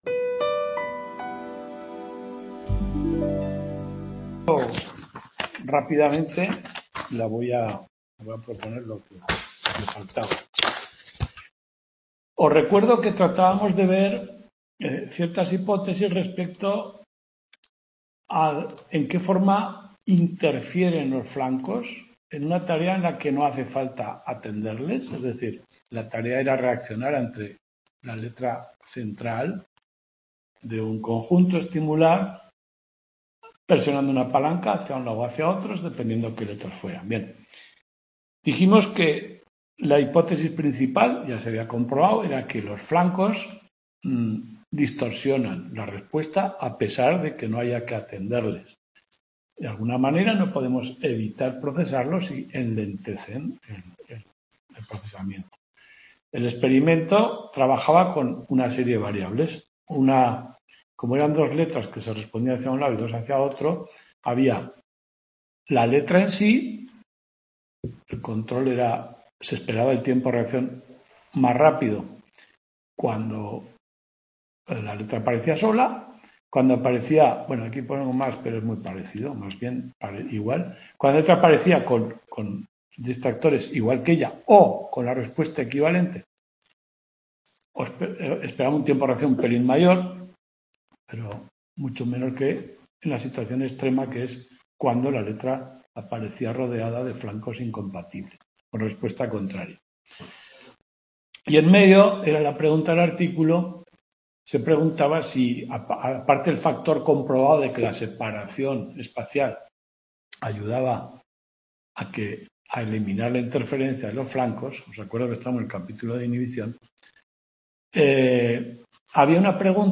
Ps. de la Atención. Tudela 06/05/2025 Description Tutoría.